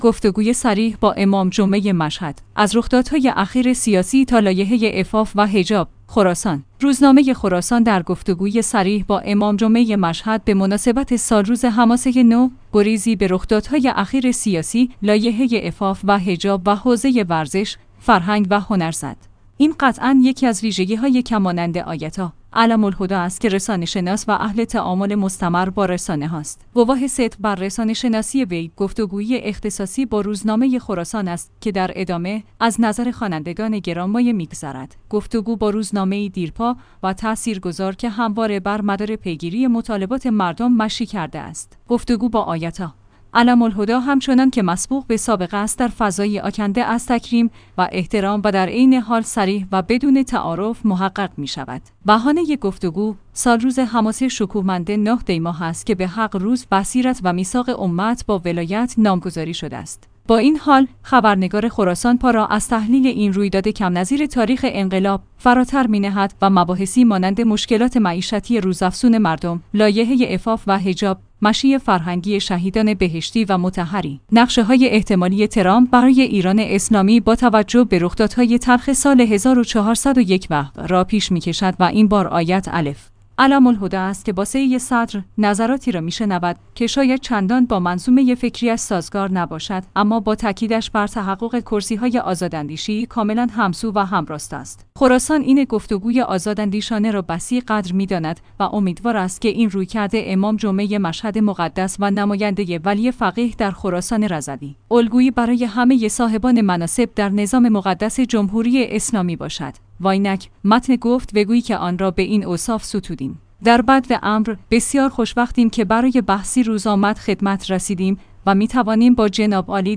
گفتگوی صریح با امام جمعه مشهد؛ از رخدادهای اخیر سیاسی تا لایحه عفاف و حجاب